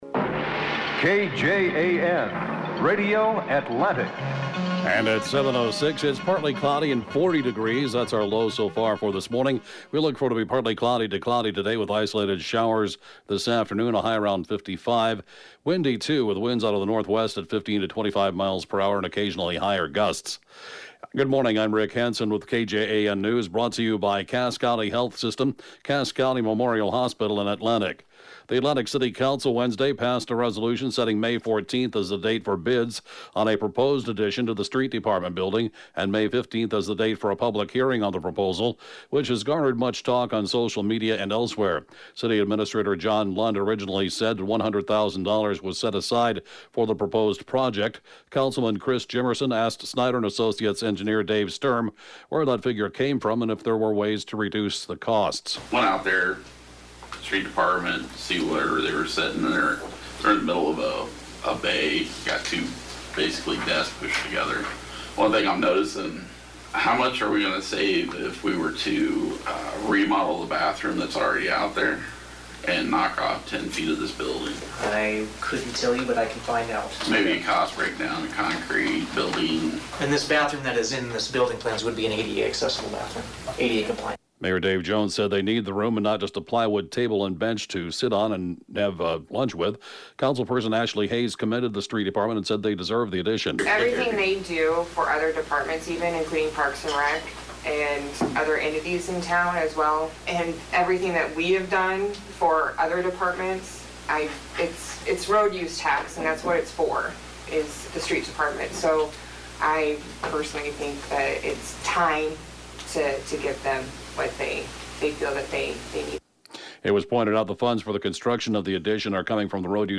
(Podcast) KJAN Morning News & Funeral report, 4/18/2019